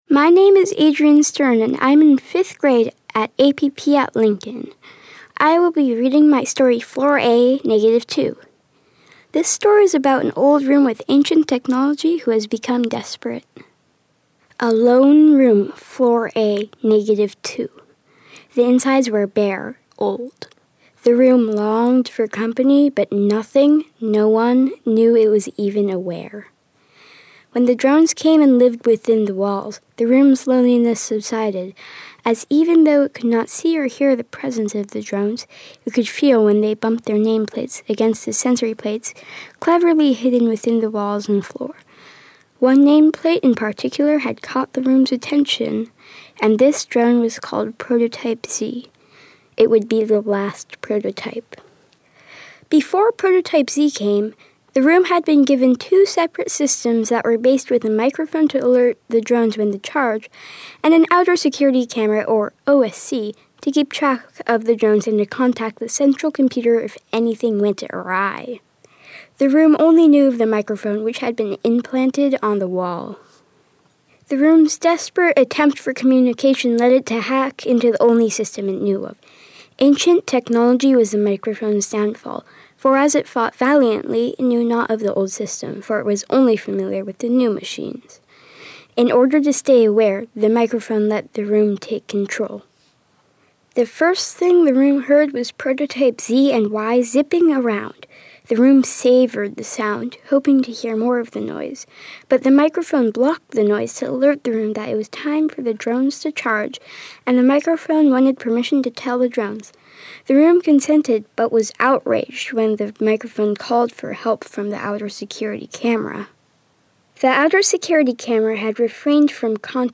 This year, MoPop and Jack Straw Cultural Center collaborated to provide winners of their annual Write Out of This World Writing Contest with a unique experience: Winners participated in a writing workshop, voice workshop, and recording session at Jack Straw, resulting in a professional recording of each writer reading their work.